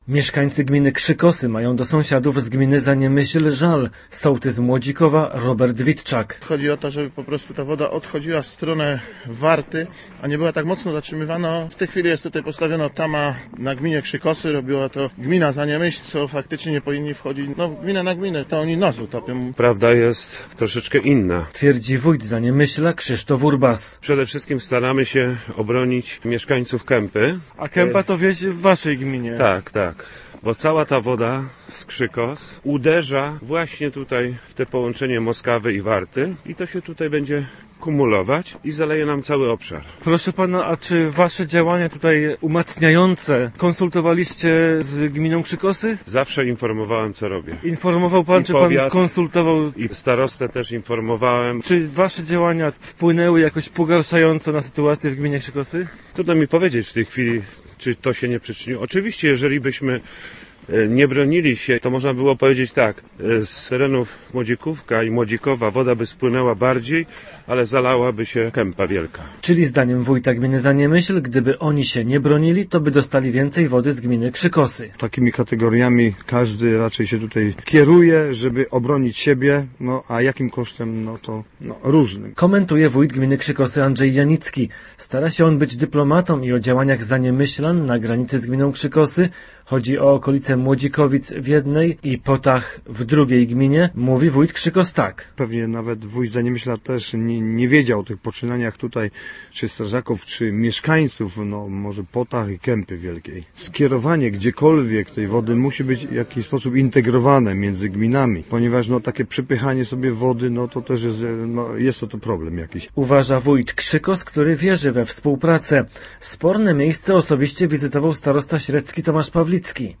Chodzi o walkę z wysoką wodą z roztopów. Pytanie stawiamy dlatego, że w powiecie średzkim o wodę z roztopów do gminy Zaniemyśl pretensje mają mieszkańcy sąsiedniej gminy Krzykosy. Po podmokłym gruncie na granicy dwóch gmin powiatu średzkiego z mikrofonem stąpał nasz reporter.